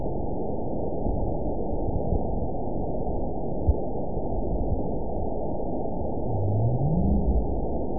event 917908 date 04/21/23 time 19:30:50 GMT (2 years ago) score 9.54 location TSS-AB04 detected by nrw target species NRW annotations +NRW Spectrogram: Frequency (kHz) vs. Time (s) audio not available .wav